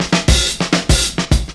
DRUMFILL03-L.wav